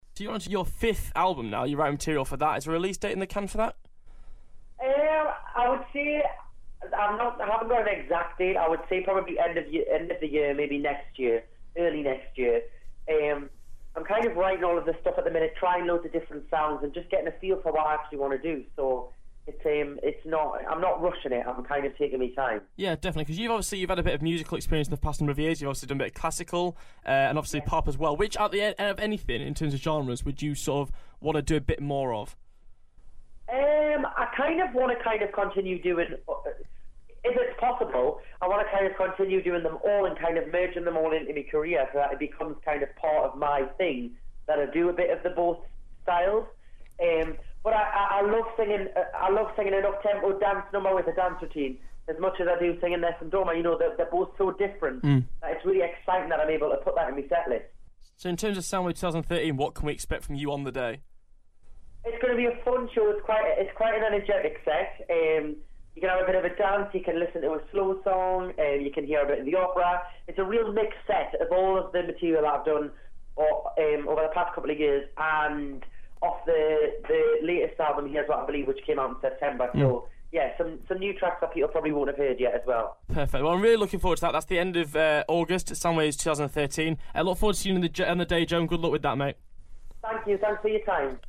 Joe Mcelderry Interview Part 2